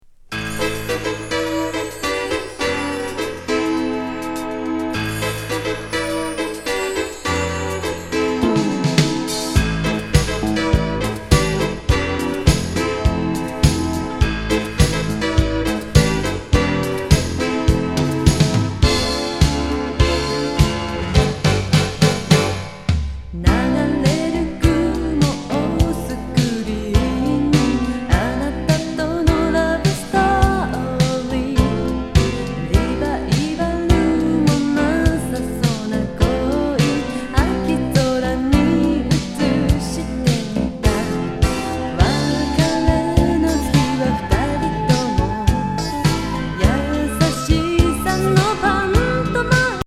グルーヴィ歌謡